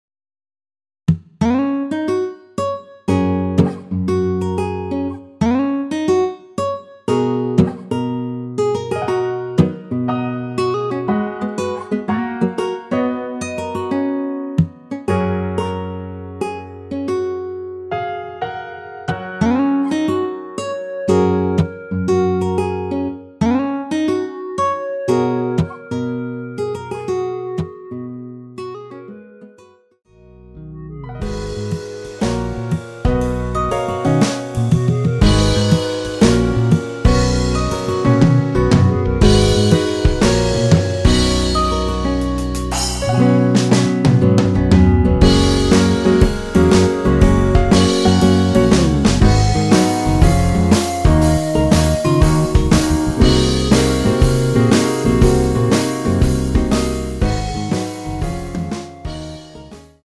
원키 멜로디 포함된 MR입니다.
F#
앞부분30초, 뒷부분30초씩 편집해서 올려 드리고 있습니다.